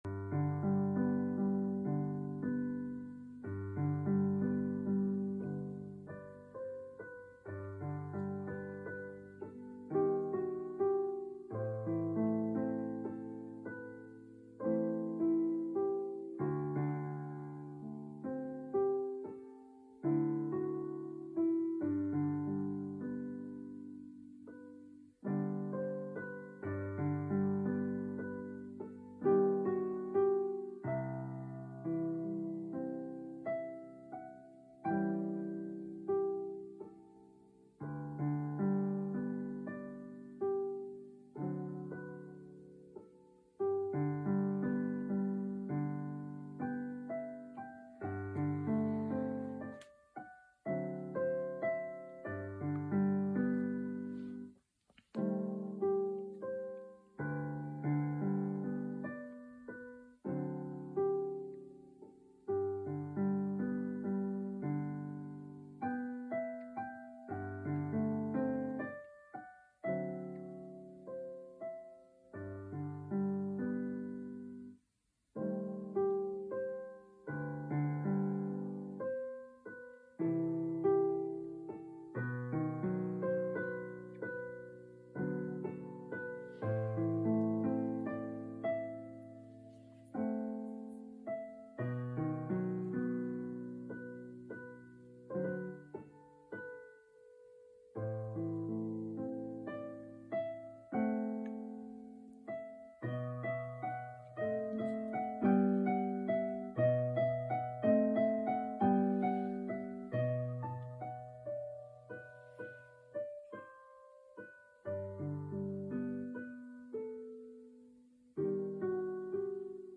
Tanulás alatti gyakorlások / Esercitazioni durante lo studio: